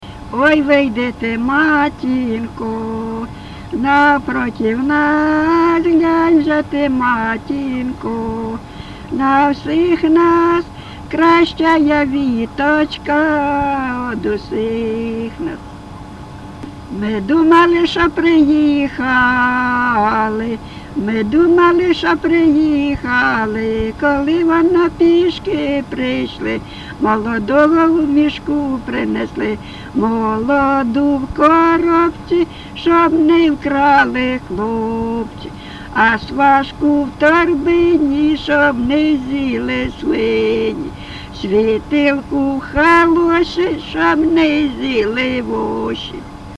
ЖанрВесільні
Місце записус. Привілля, Словʼянський (Краматорський) район, Донецька обл., Україна, Слобожанщина